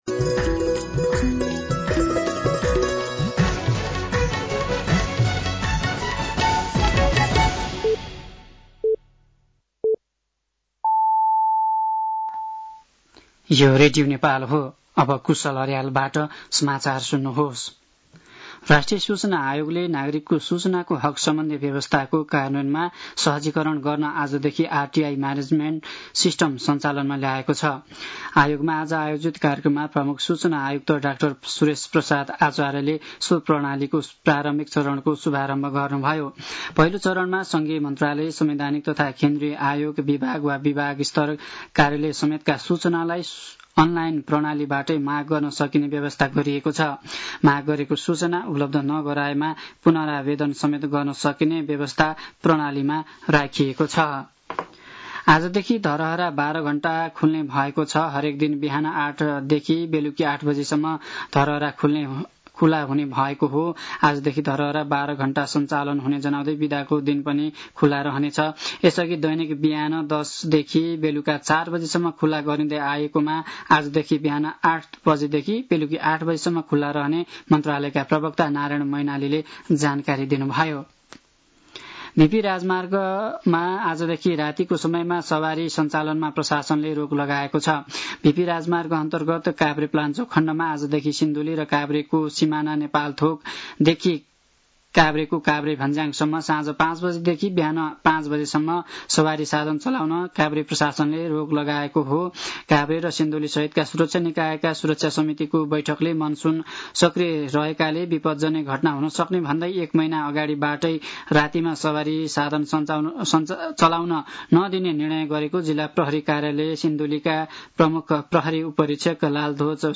An online outlet of Nepal's national radio broadcaster
साँझ ५ बजेको नेपाली समाचार : १ साउन , २०८२